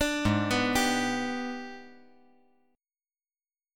AbmM7b5 Chord
Listen to AbmM7b5 strummed